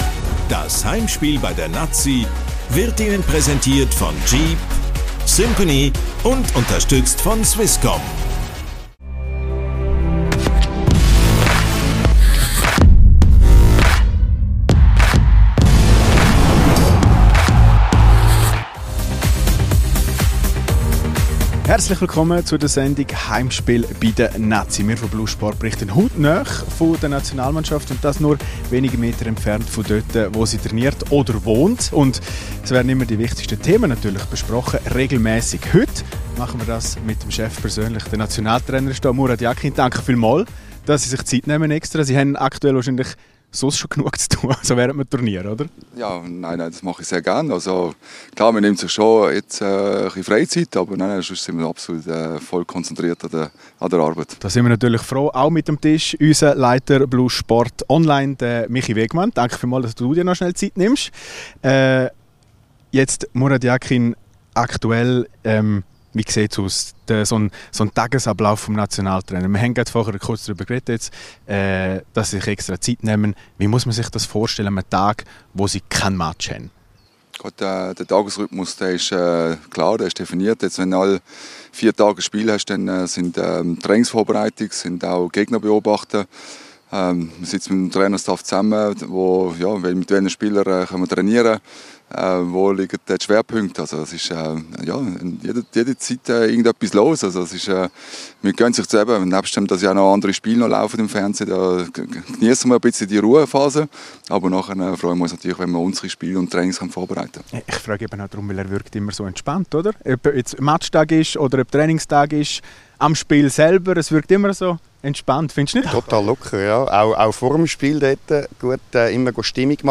Heute ist Murat Yakin zu Gast im Fussball-Talk «Heimspiel bei der Nati» von blue Sport.